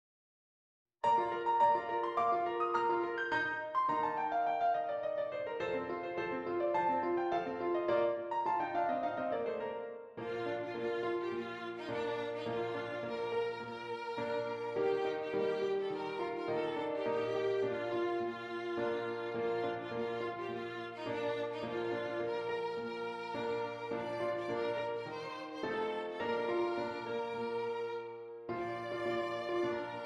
Violin Solo with Piano Accompaniment
B Flat Major
Allegro moderato